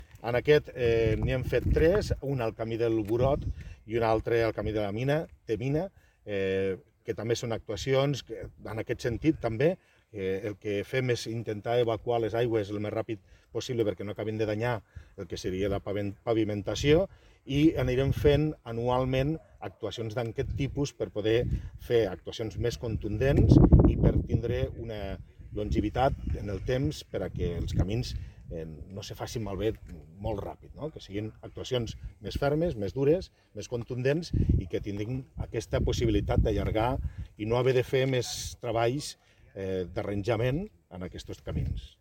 tall-de-veu-del-regidor-joan-ramon-castro-sobre-la-millora-del-ferm-que-ha-fet-la-paeria-en-tres-camins-de-l2019horta